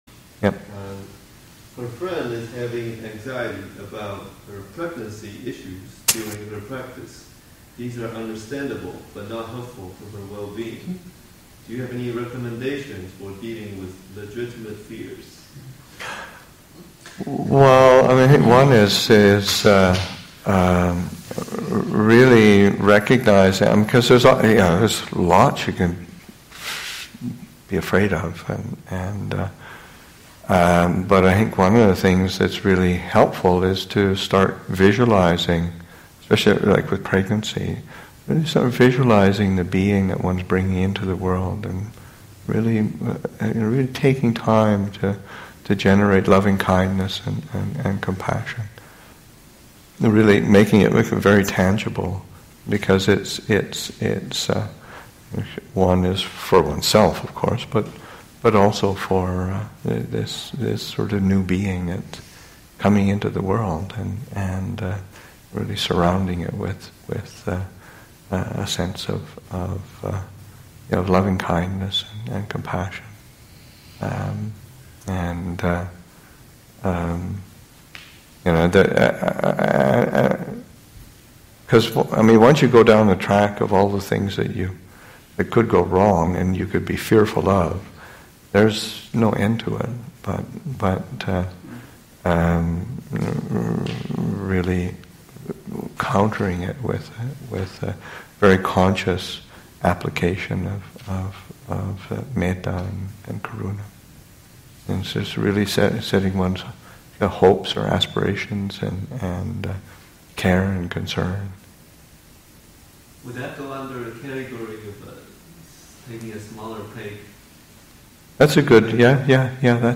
Follow-up: “It seems really difficult to think of Dhamma or the refuges while in such a wrapped-up state. I don’t know if I could do that.” [Noble Truth of Suffering] [Visualization] [Goodwill] [Compassion]